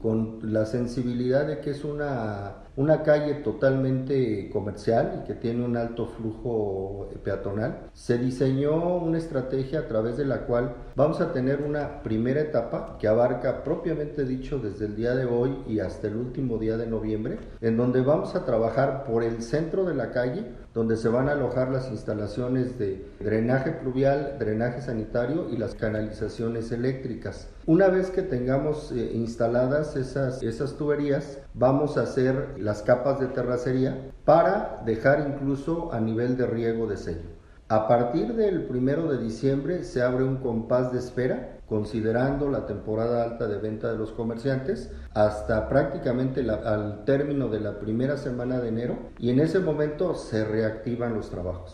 AudioBoletines
Manuel Venegas Pérez Director de Obra Pública